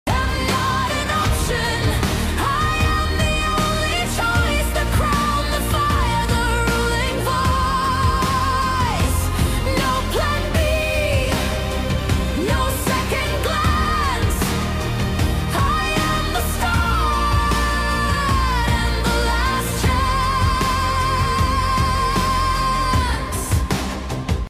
powerful dark feminine anthem